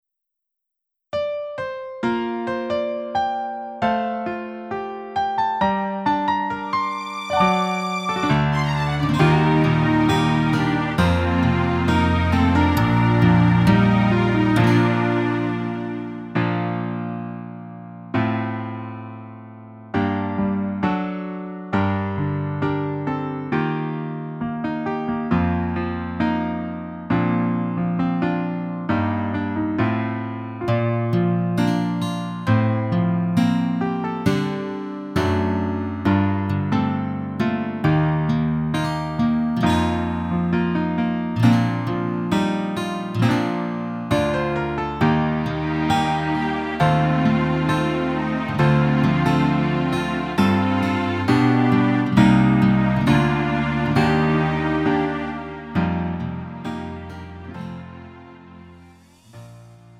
음정 원키 3:28
장르 가요 구분 Lite MR